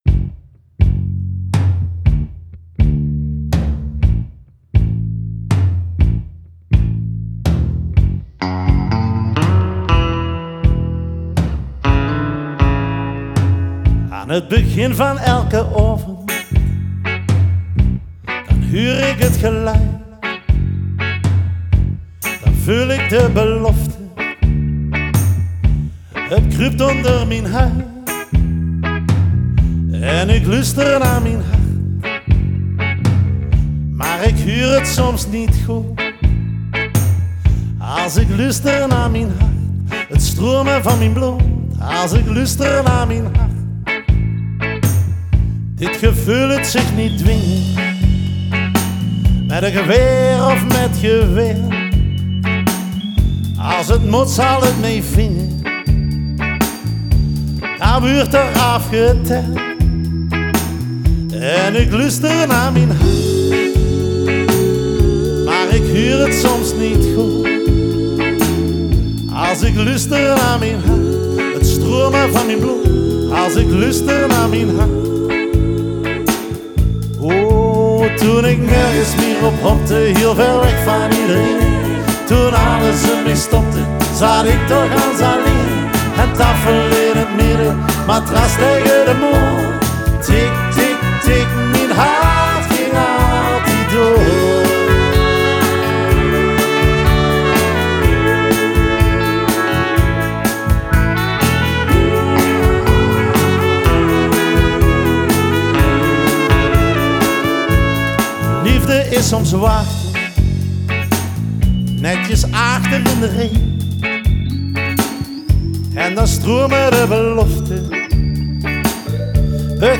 Они поют на северном нижне-франкском диалекте.
Genre: Ballad, folk